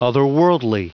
Prononciation du mot otherworldly en anglais (fichier audio)
Prononciation du mot : otherworldly